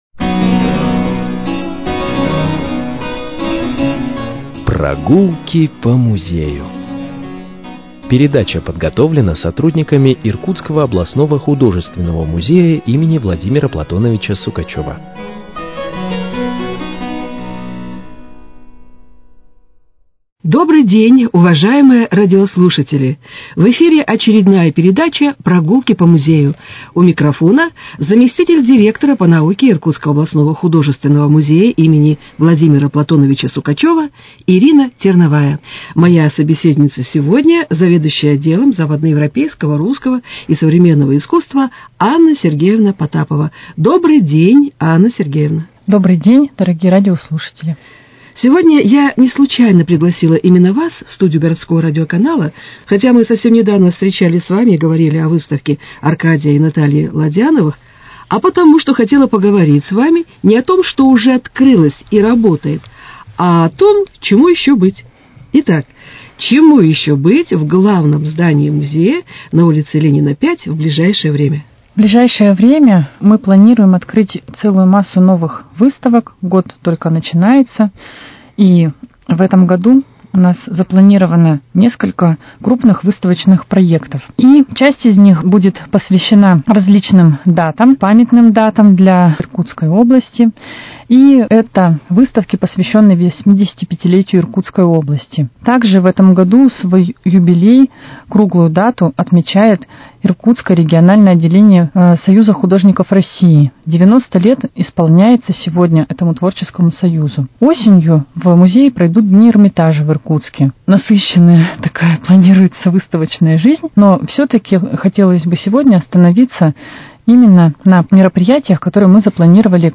Прогулки по музею: Беседа о планах музея